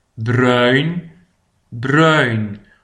bruin PRONONCIATION